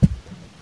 Tags: 808 drum cat kick kicks hip-hop